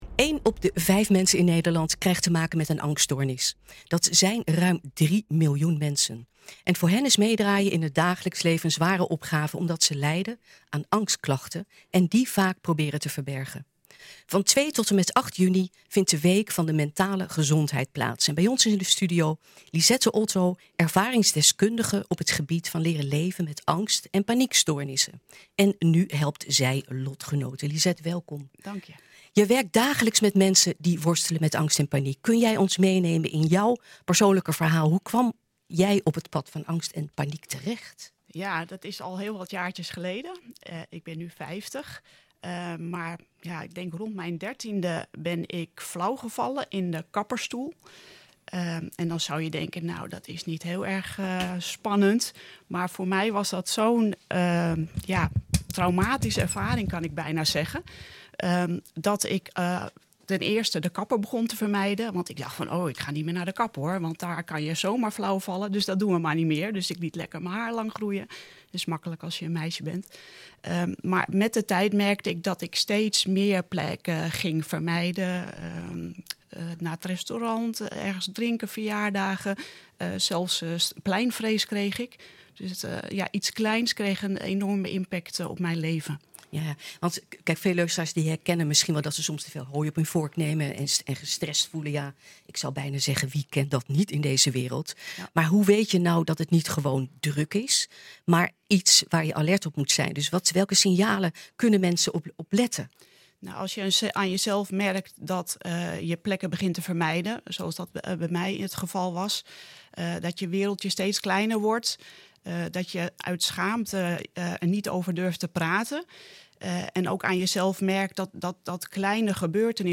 Bij ons in de studio: